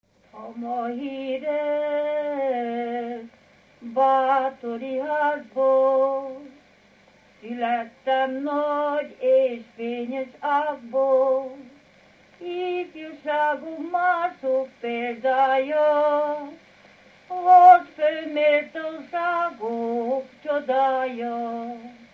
Erdély - Udvarhely vm. - Szentegyházasfalu
Stílus: 8. Újszerű kisambitusú dallamok
Szótagszám: 9.9.9.9
Kadencia: 2 (2) 3 1